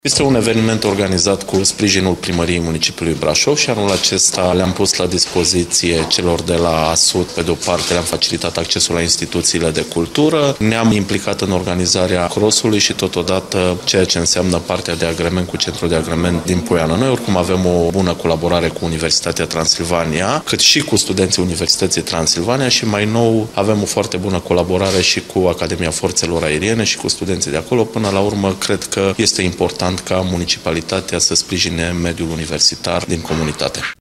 Festivalului „Săptămâna Studenților Brașoveni” este organizat de Asociația Studenților din Universitatea Transilvania cu sprijinul Primăriei Brașov, arată viceprimarul Costel Mihai: